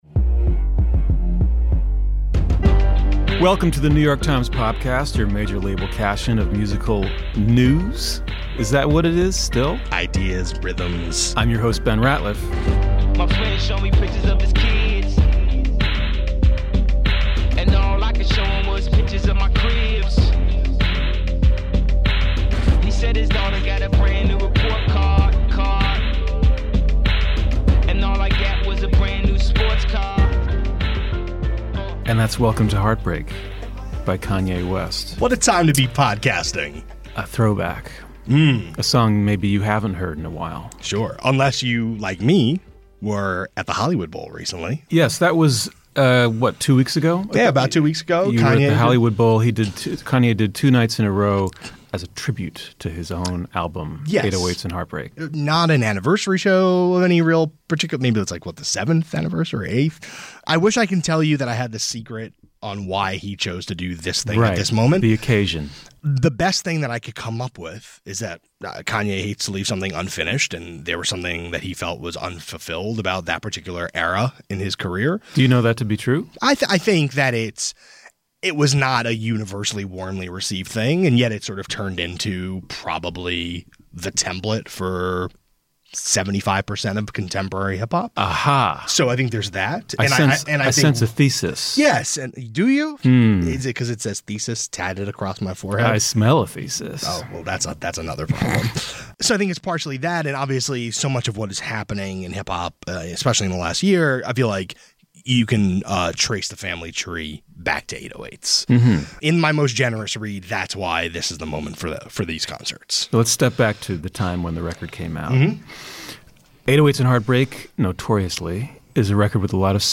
Times music critics discuss the influence of Kanye West’s album on current musical styles.